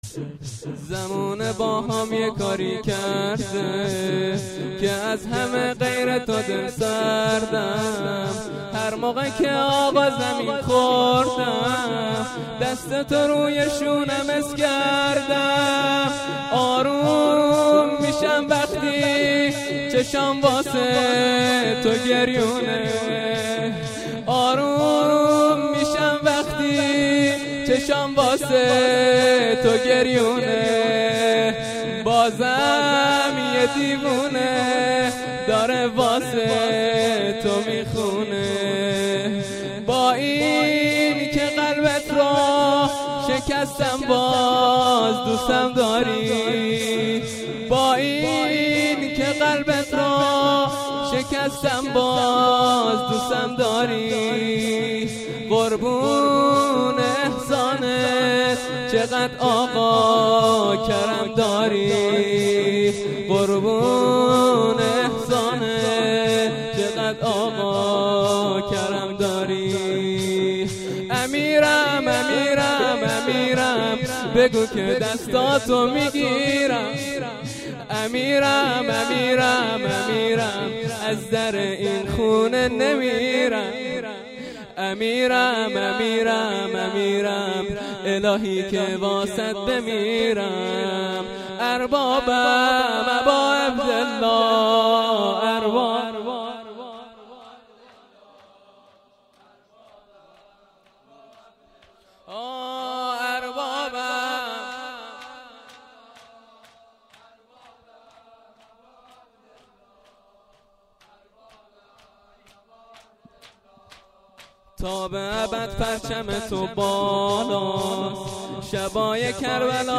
مراسم هفتگی مجمع رهروان حضرت زینب س